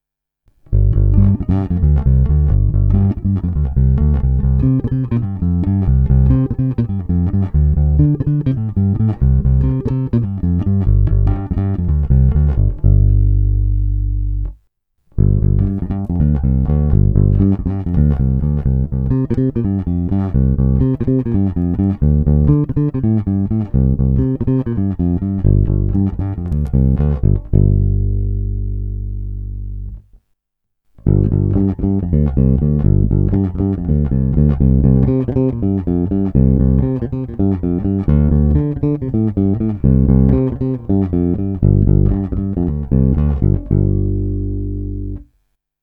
Zvonivý, vrnivý, pevný.
Není-li uvedeno jinak, následující nahrávky jsou provedeny rovnou do zvukovky a kromě normalizace ponechány bez dodatečných úprav.
Hráno mezi snímačem a kobylkou